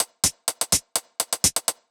Index of /musicradar/ultimate-hihat-samples/125bpm
UHH_ElectroHatD_125-03.wav